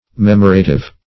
Search Result for " memorative" : The Collaborative International Dictionary of English v.0.48: Memorative \Mem"o*ra*tive\, a. [Cf. F. m['e]moratif.]